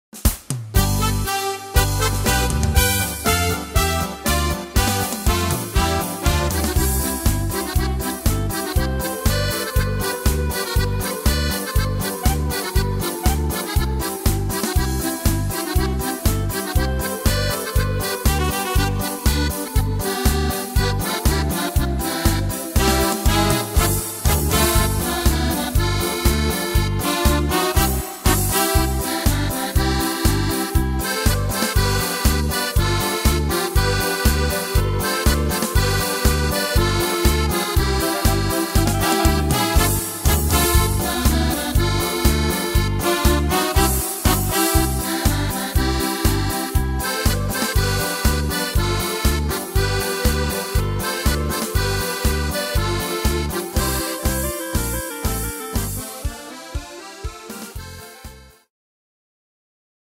Tempo: 120 / Tonart: C-Dur